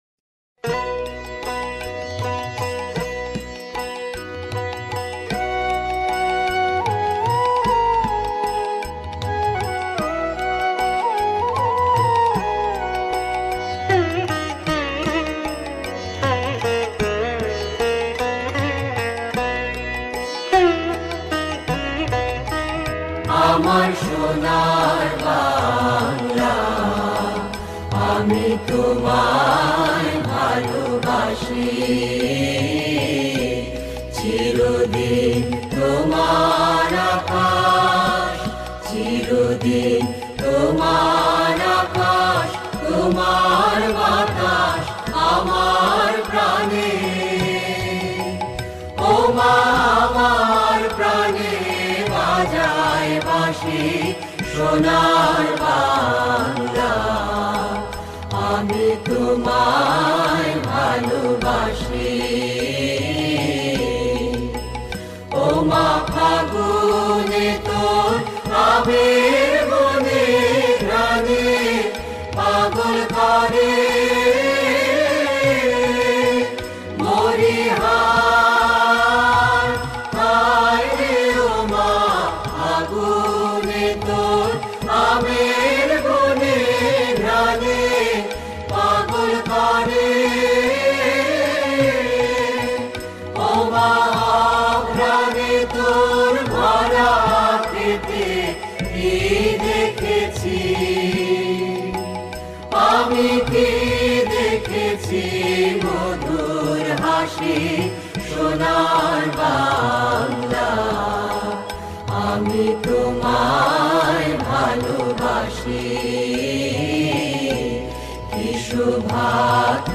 Музыка с измененным вокалом народа Бангладеш